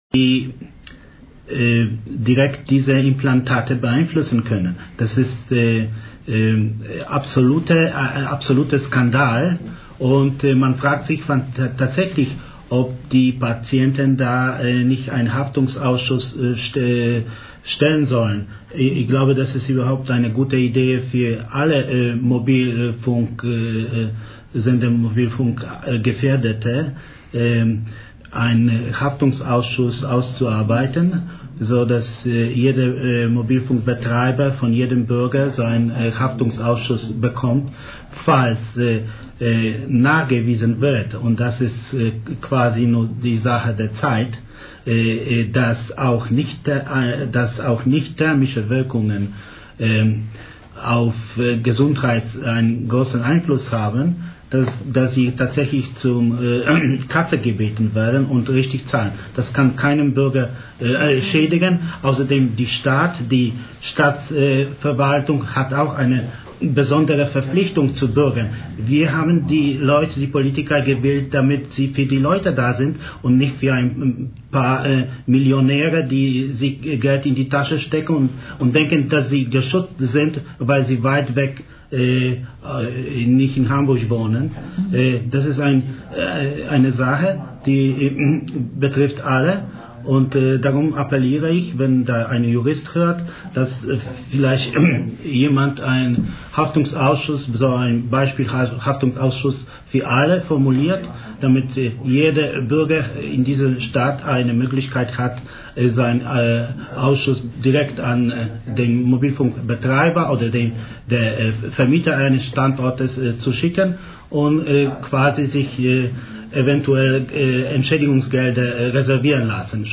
Am Dienstag den 25.März 2003 ab 17-19 Uhr auf FSK FM 93.0 Mhz und im Hamburger Kabelnetz auf 101,4 MHz diskutierten wir im Radio.